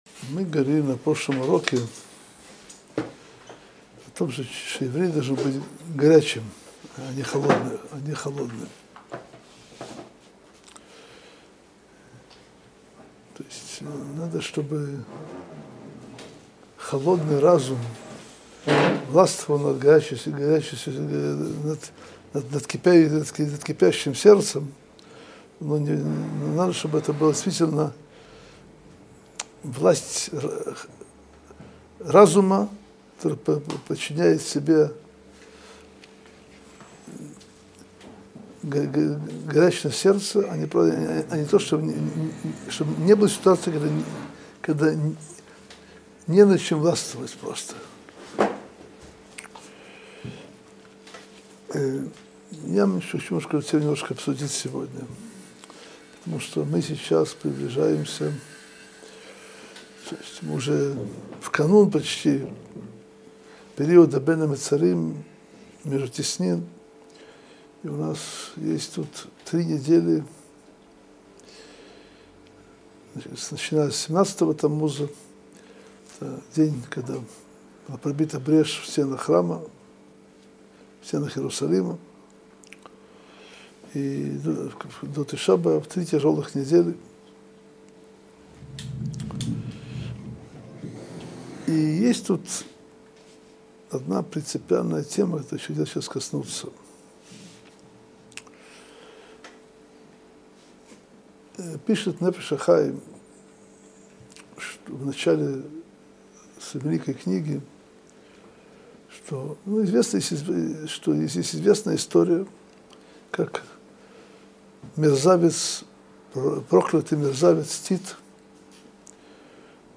Содержание урока: Как Тит убил Бога народа Израиля?